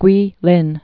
(gwēlĭn, gwā-) also Kwei·lin (kwā-)